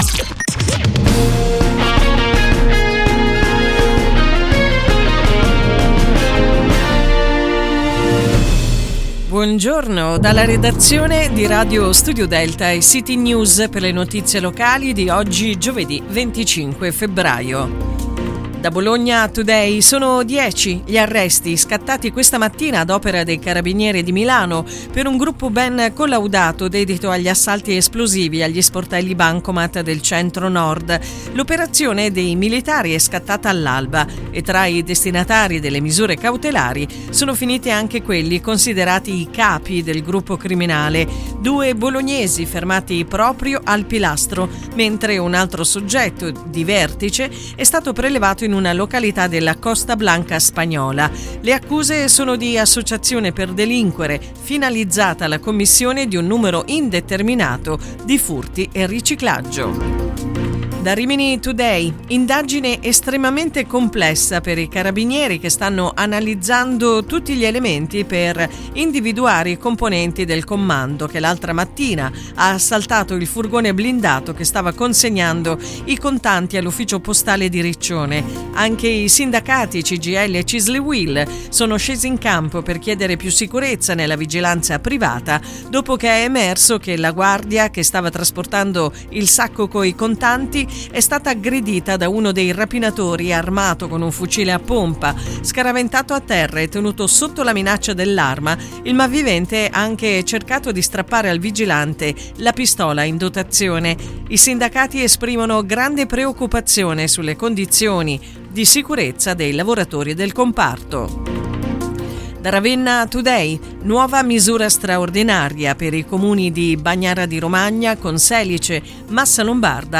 Ascolta le notizie locali di Radio Studio Delta in collaborazione con CityNews